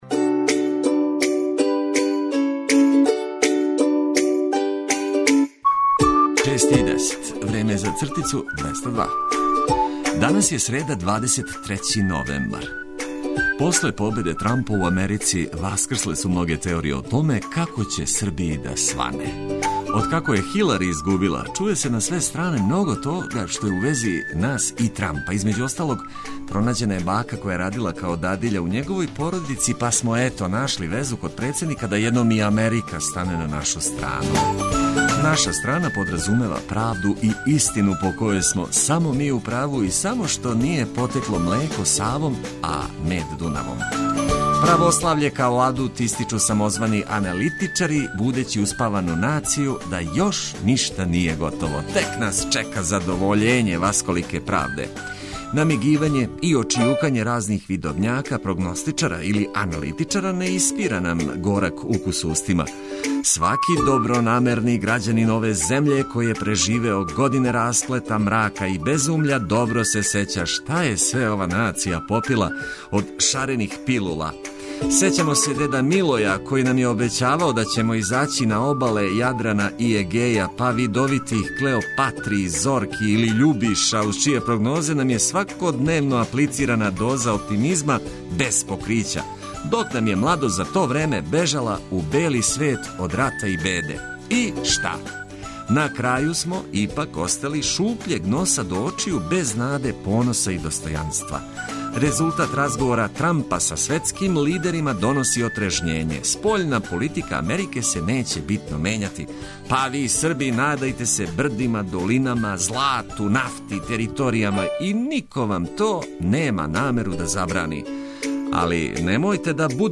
Буђење је пријатније уз ведре тонове и одлично расположене радио пријатеље.